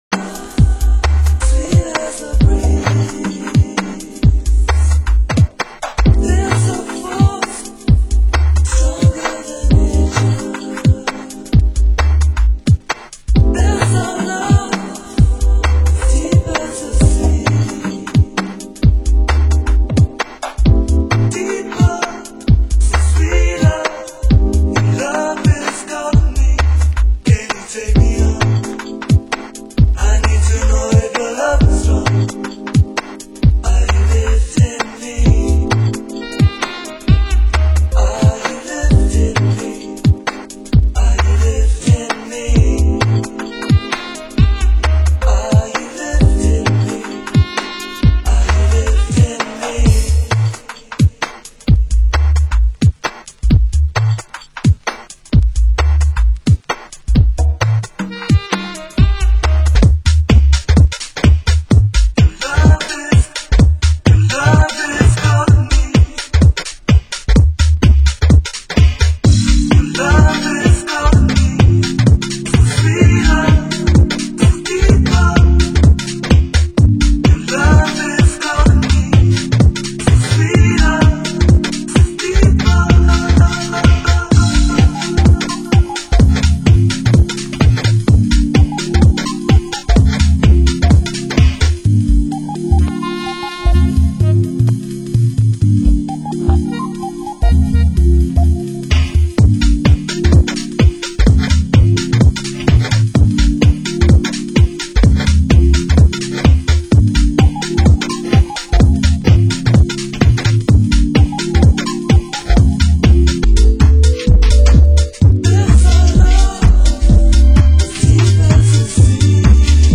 Genre: UK Garage